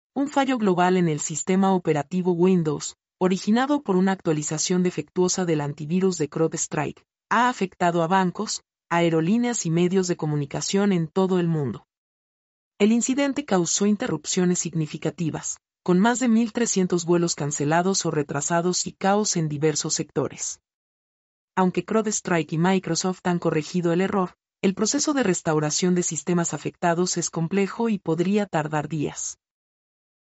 mp3-output-ttsfreedotcom-28-1.mp3